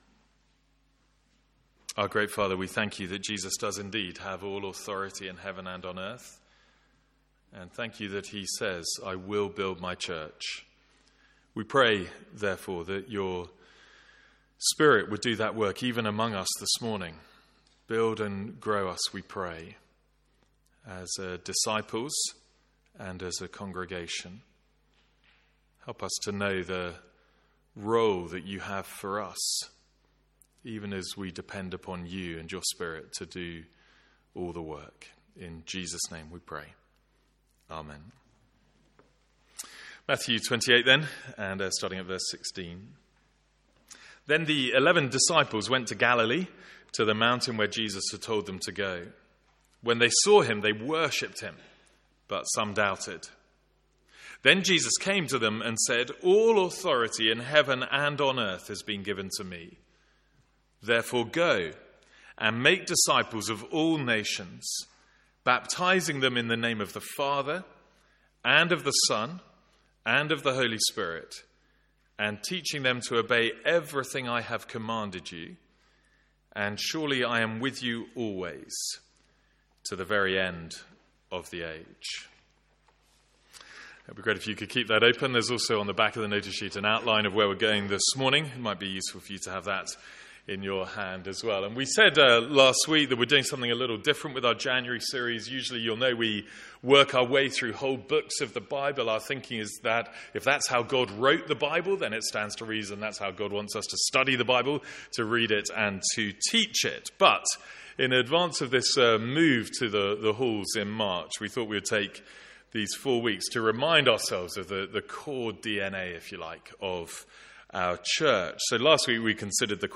From our morning series on 'The Church'.